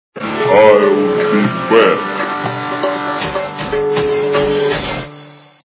» Звуки » звуки для СМС » Прикольная SMS - Ill be back
При прослушивании Прикольная SMS - Ill be back качество понижено и присутствуют гудки.